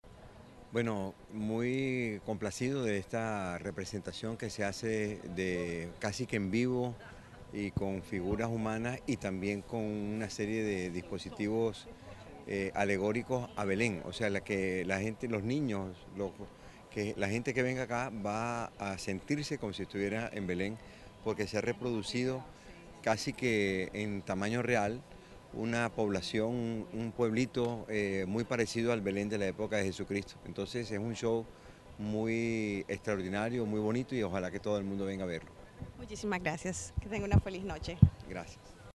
ENTREVISTA-GOBERNADOR-VOLVER-A-BELEN.mp3